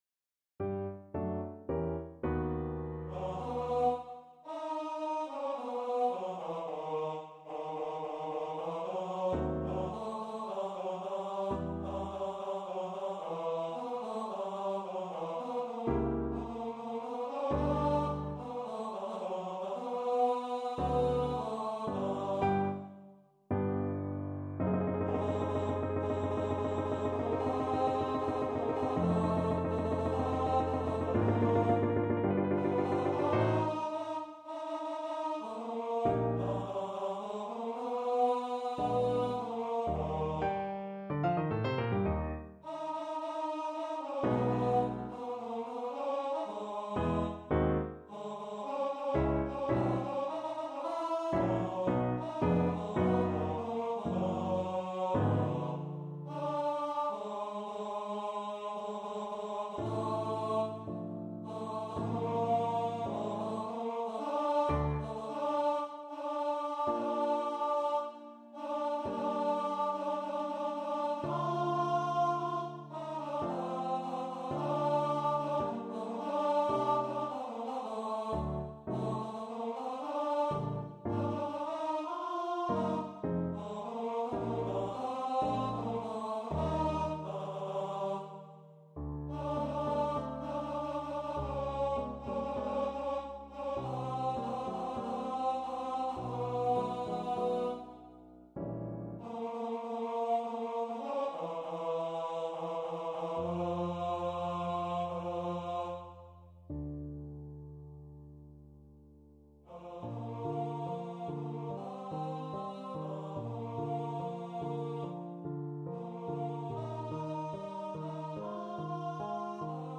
Allegro =110 (View more music marked Allegro)
4/4 (View more 4/4 Music)
Eb4-Bb5
Classical (View more Classical Voice Music)